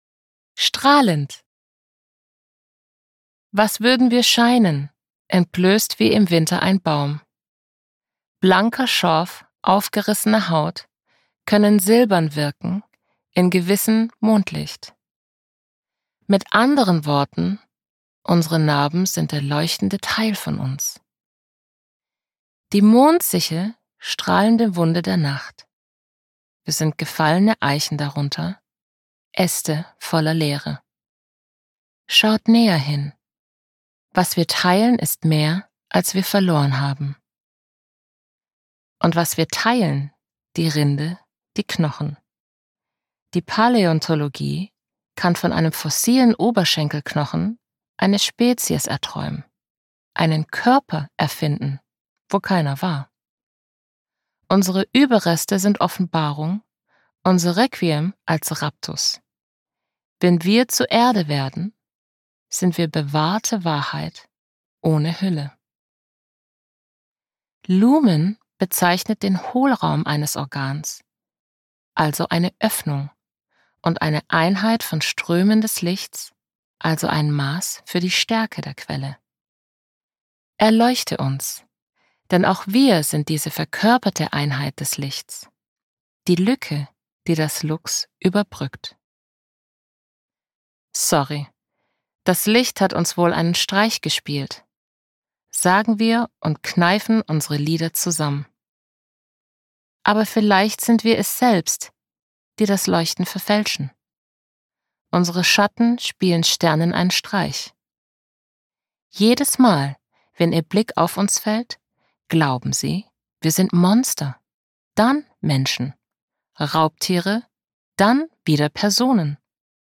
Cassandra Steen (Sprecher)
2022 | Ungekürzte Lesung